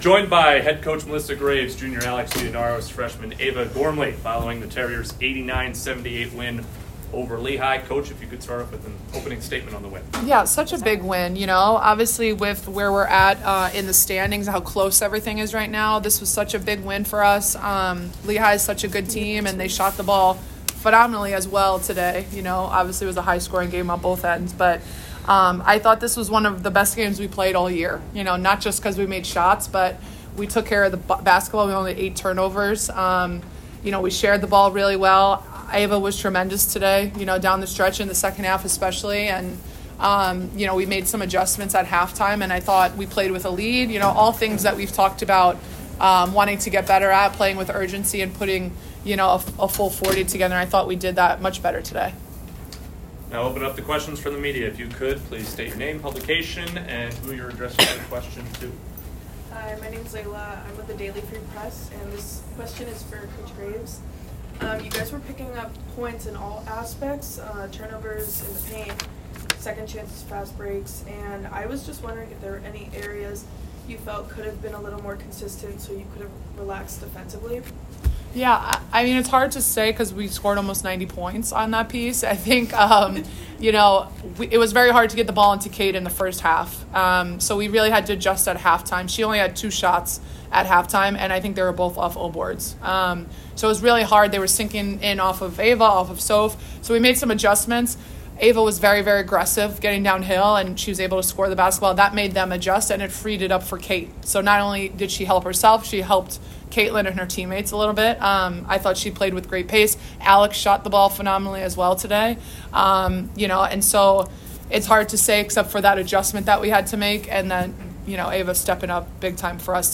Women's Basketball / Lehigh Postgame Press Conference (2-28-24)